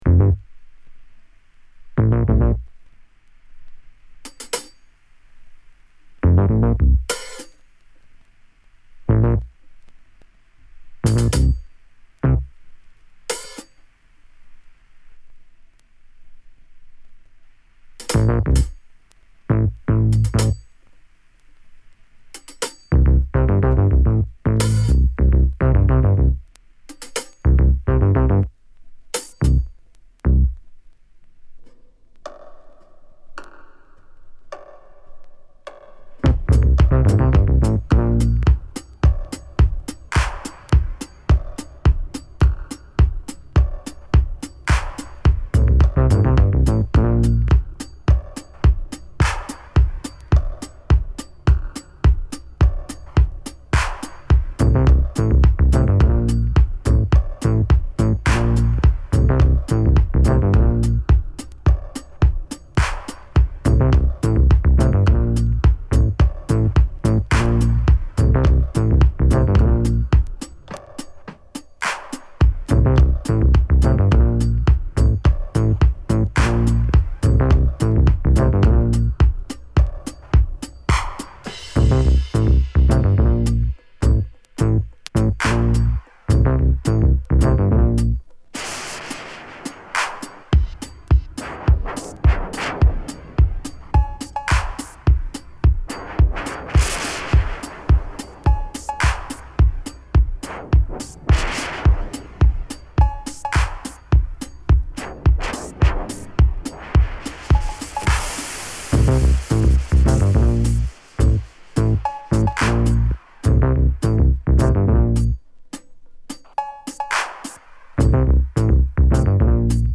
無国籍のエキゾ・ファンタジー、スペースファンク！